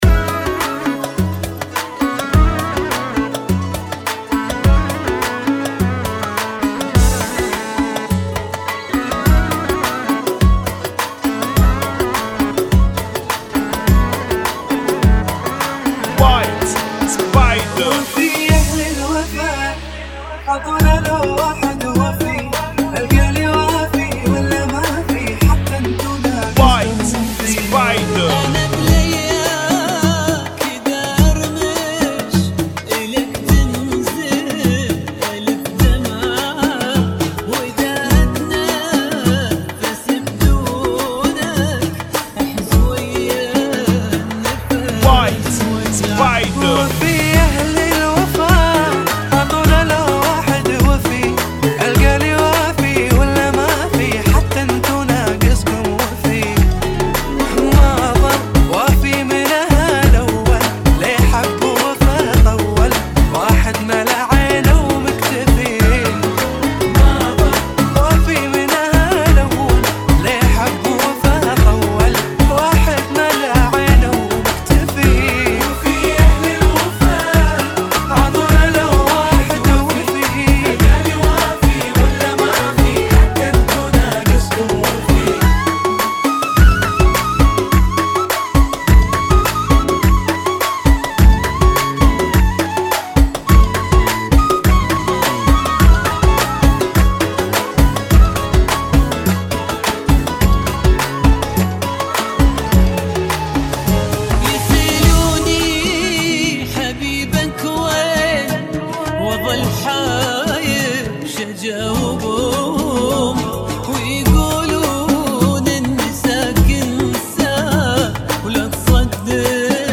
[ 104 Bpm ]
Funky